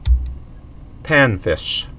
(pănfĭsh)